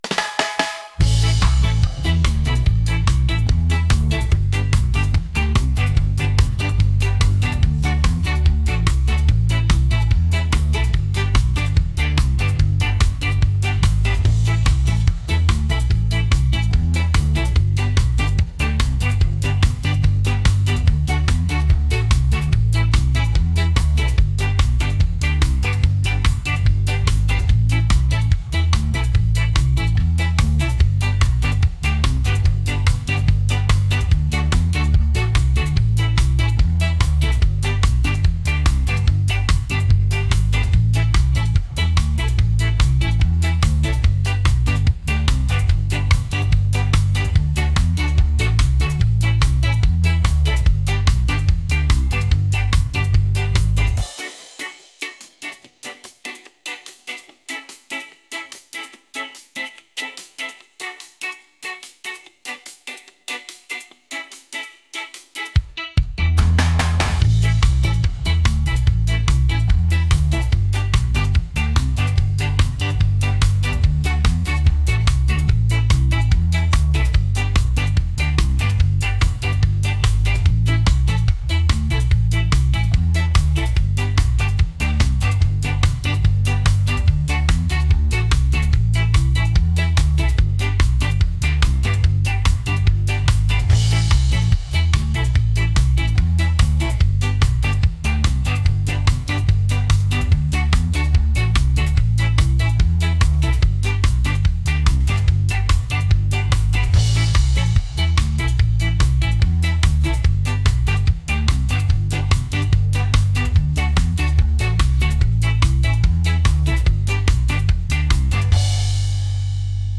reggae | upbeat